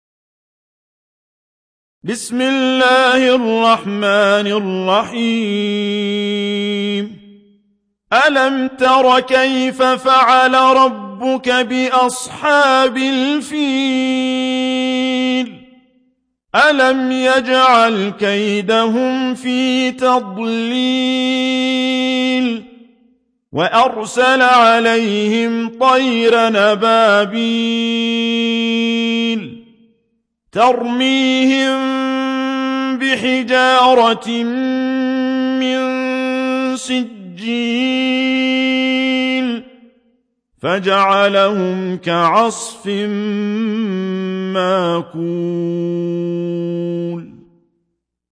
Surah Sequence تتابع السورة Download Surah حمّل السورة Reciting Murattalah Audio for 105. Surah Al-F�l سورة الفيل N.B *Surah Includes Al-Basmalah Reciters Sequents تتابع التلاوات Reciters Repeats تكرار التلاوات